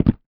IMPACT_Stone_Deep_mono.wav